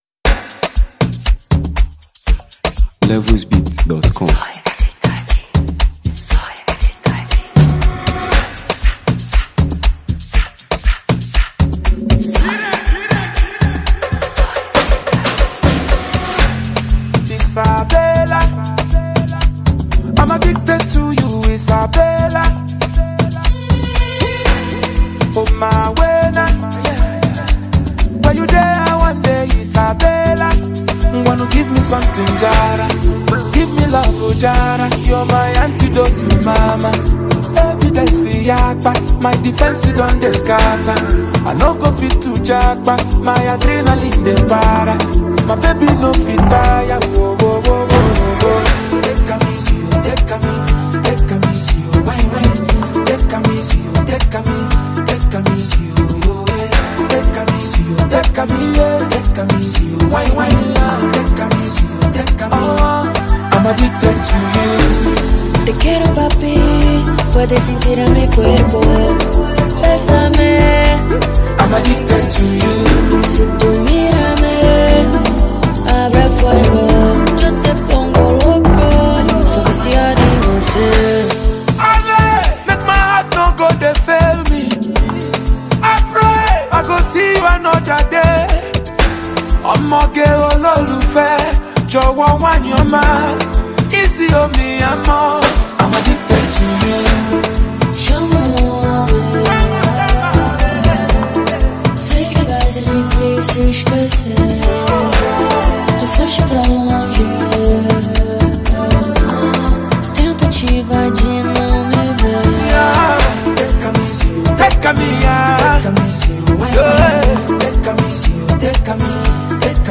delivers a smooth and enchanting performance
and refined melody to the song.
soothing instrumentation